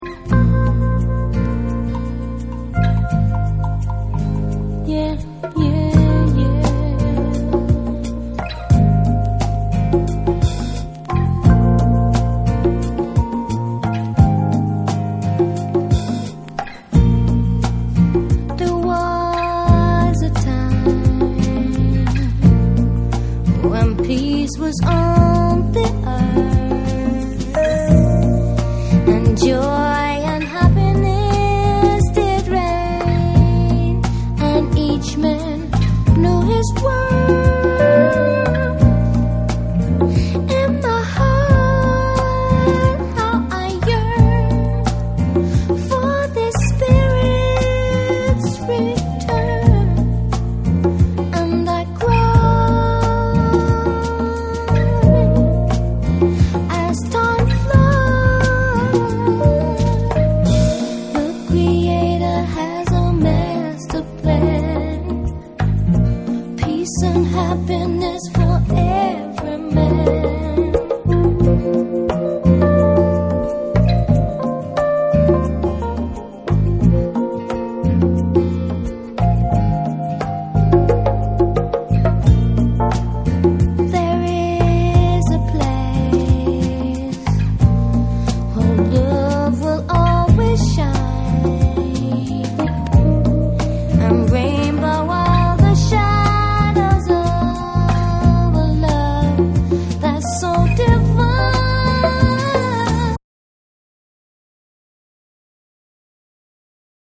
BREAKBEATS / JAPANESE